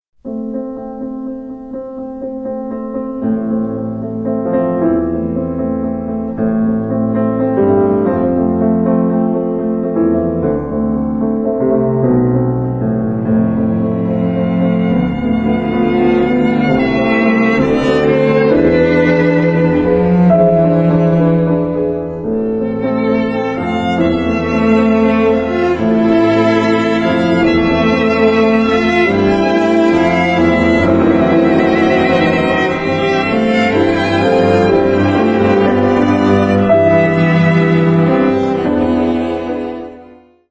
Gustav MAHLER: Klavierquartett